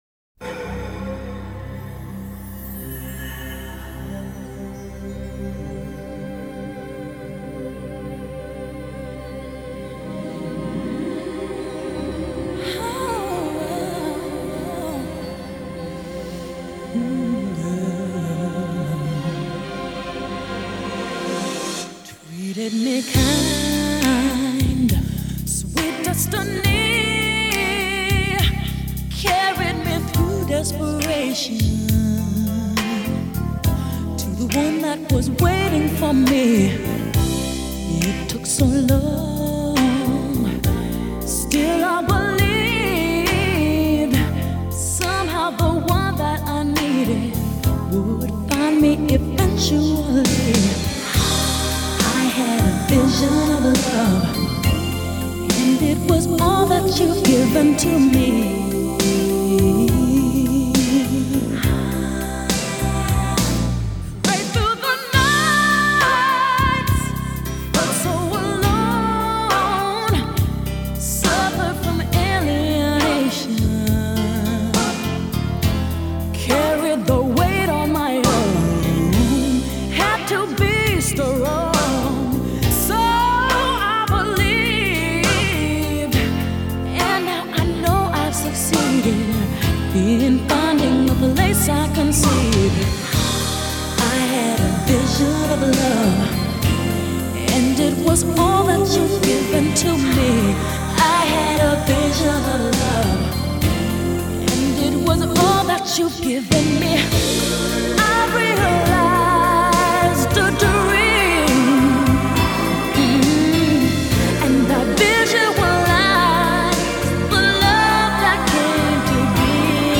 其假声甚高，且运用花腔唱法，足见她演唱技艺的精湛，